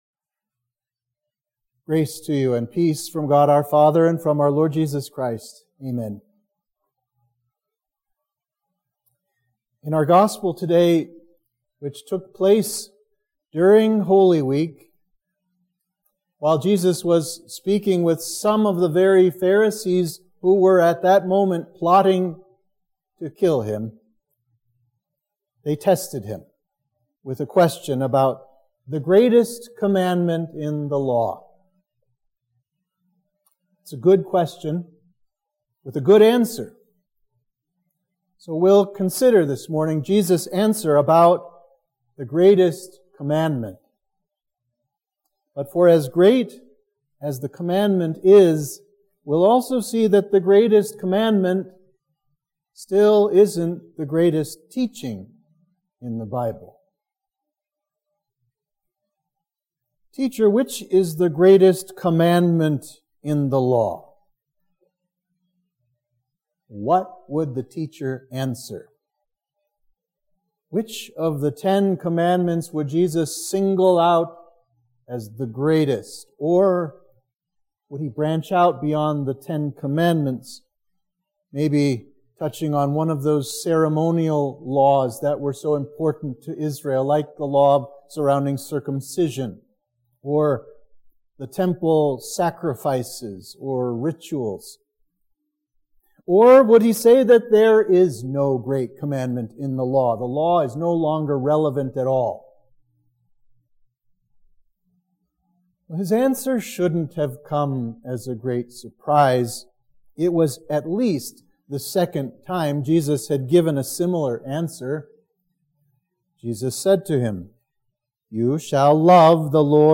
Sermon for Trinity 18